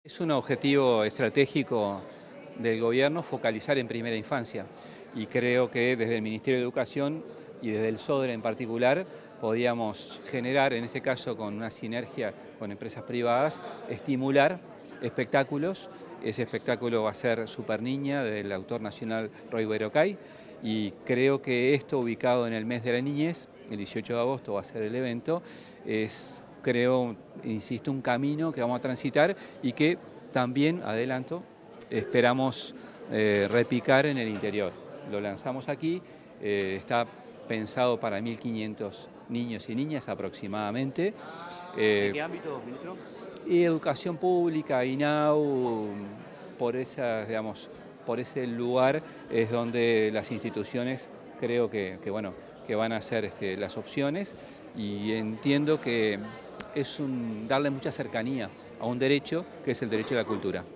Declaraciones del ministro de Educación y Cultura, José Mahía
El ministro de Educación y Cultura, José Mahía, dialogó con los medios de comunicación, tras el lanzamiento de la iniciativa Infancias al Teatro.